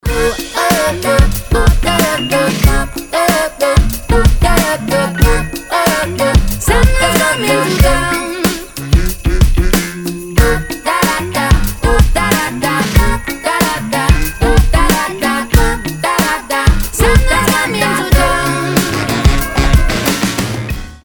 поп
позитивные
женский вокал
добрые
праздничные
звонкие
рождественские